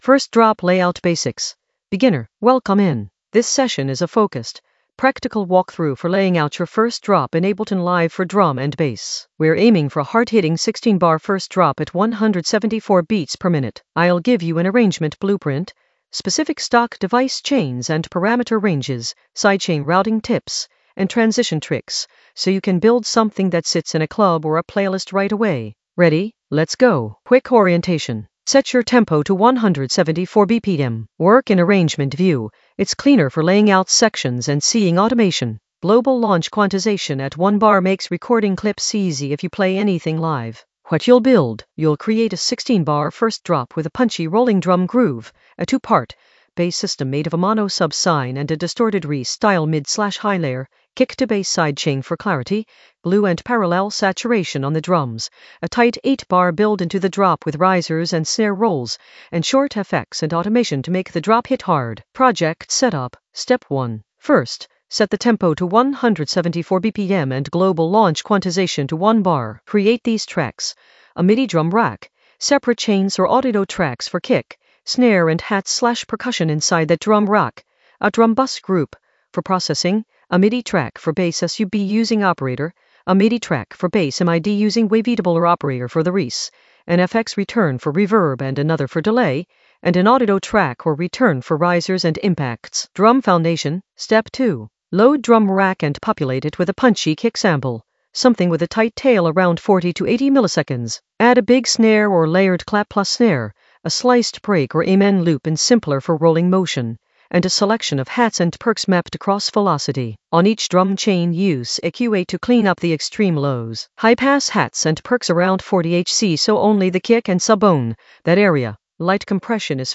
An AI-generated beginner Ableton lesson focused on First drop layout basics in the Arrangement area of drum and bass production.
Narrated lesson audio
The voice track includes the tutorial plus extra teacher commentary.